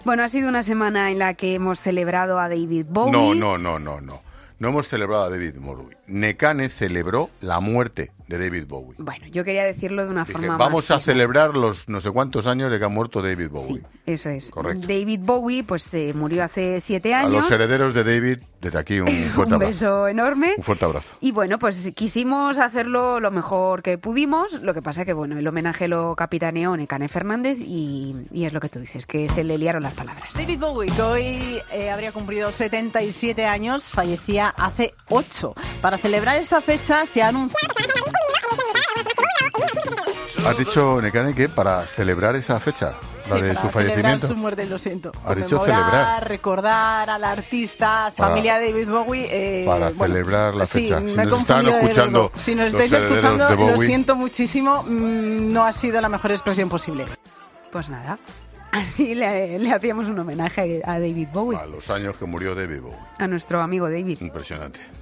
Expósito manda un mensaje a la familia de David Bowie tras un error en directo: "No es la mejor expresión"
El director de La Linterna ha dedicado unas palabras "a los herederos" del artista británico al escuchar lo que sucedía en directo mientras se recordaban fechas clave de su vida